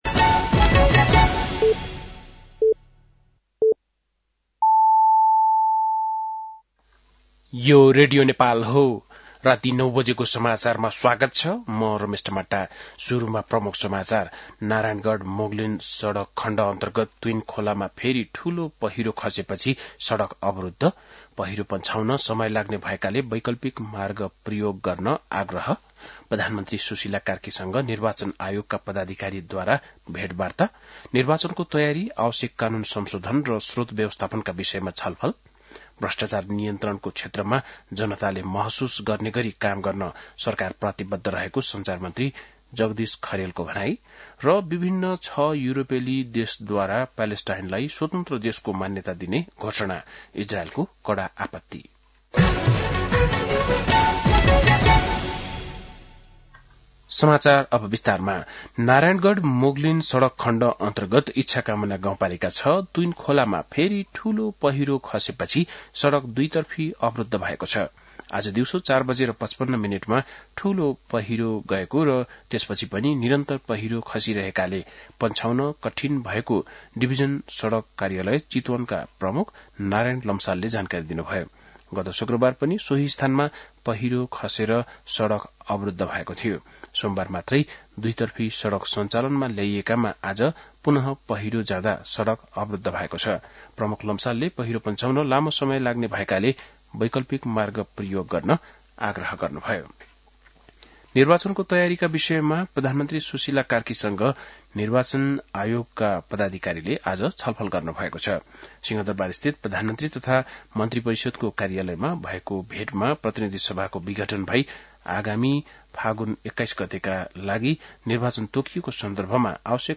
बेलुकी ९ बजेको नेपाली समाचार : ७ असोज , २०८२